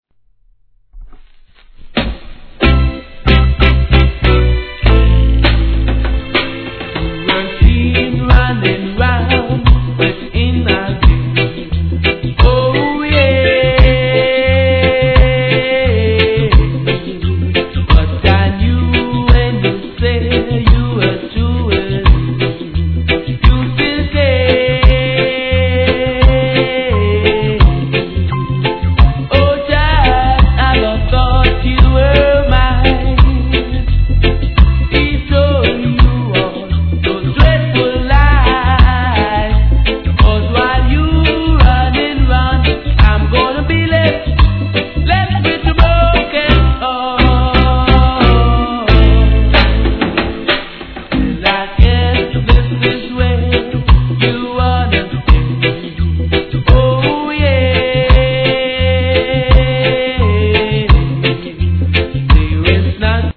REGGAE
超名曲カヴァー♪